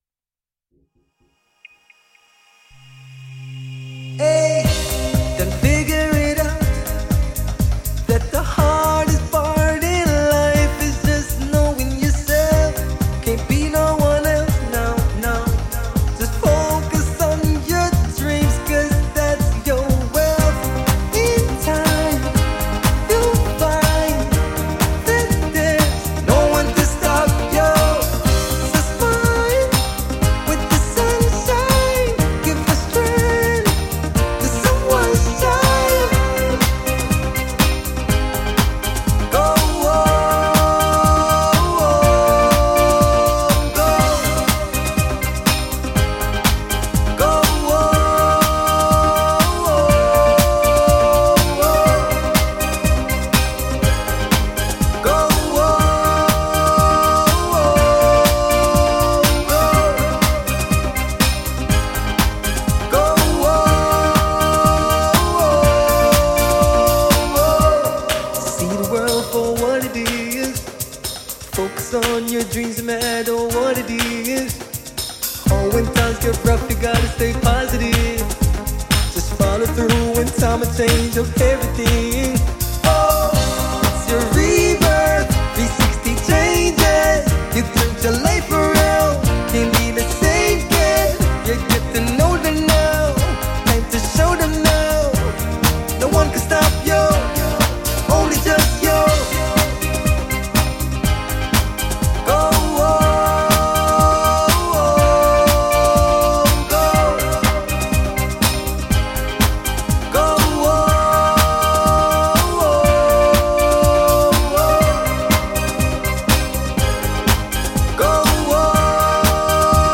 pop/dance song